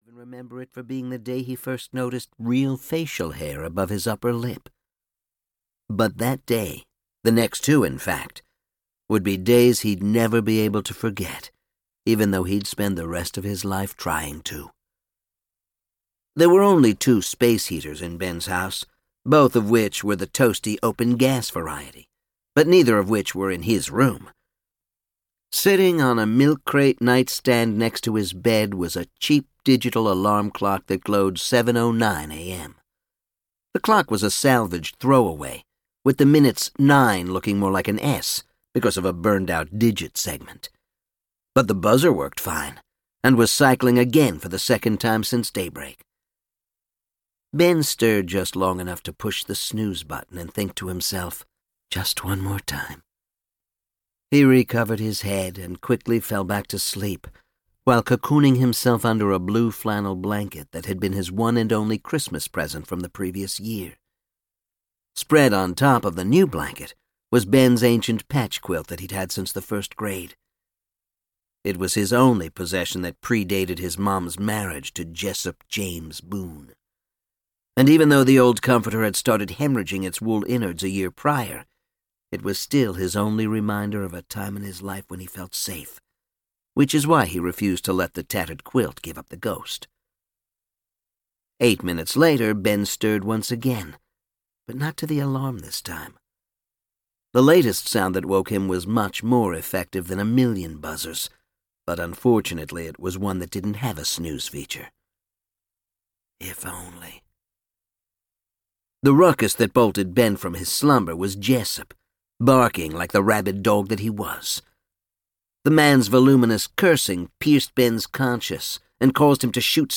Black Machetes (EN) audiokniha
Ukázka z knihy